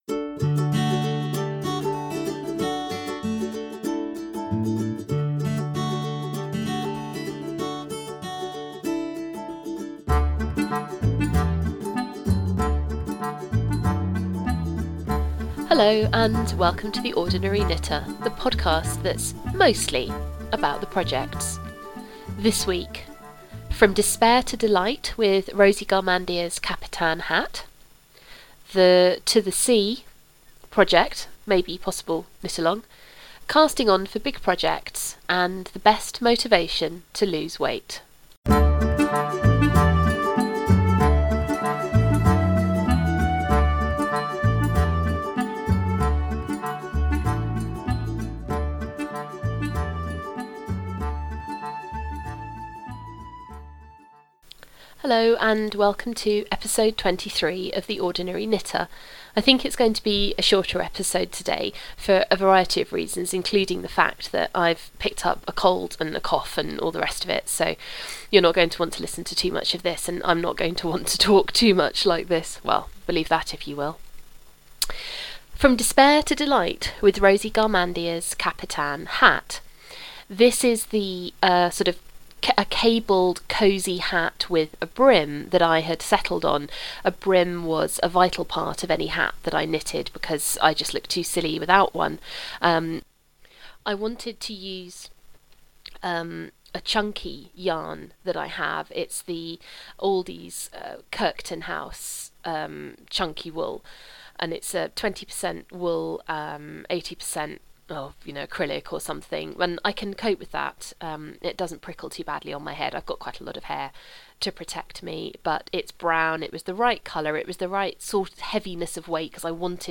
Please forgive my thick voice